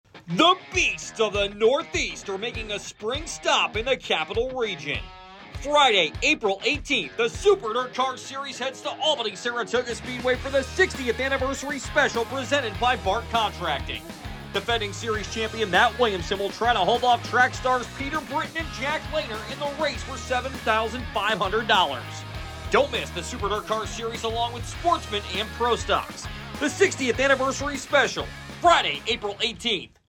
A-S-Radio-Spot-for-4_18.mp3